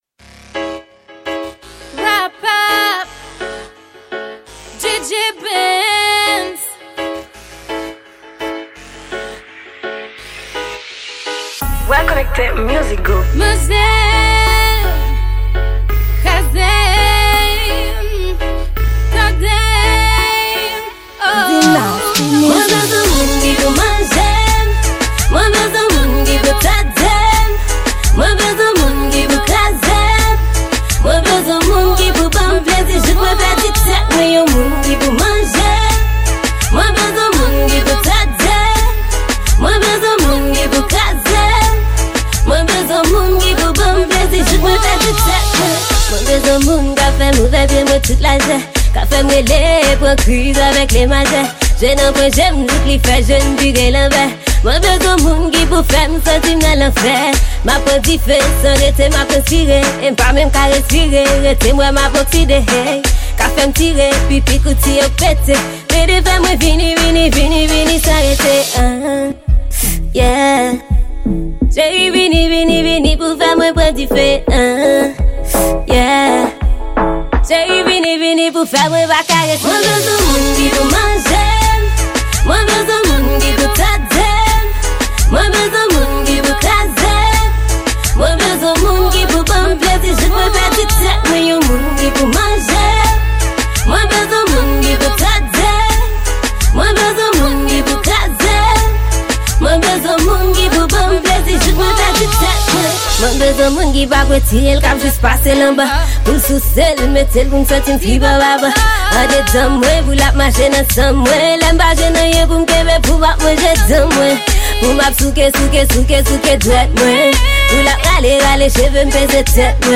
Genre: Dance Hall.